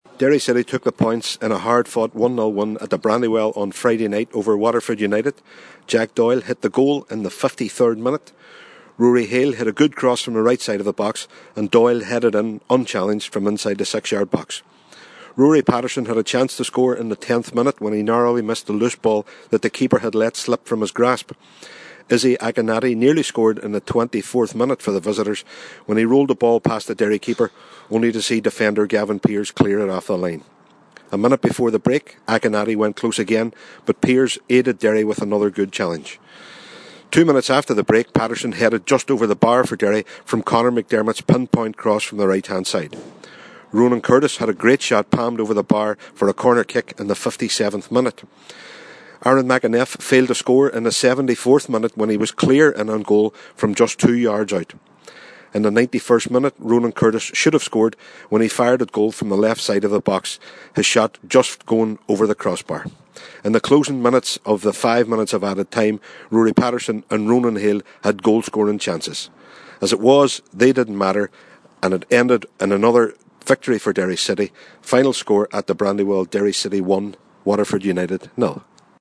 Derry City 1 v 0 Waterford Utd: FT Report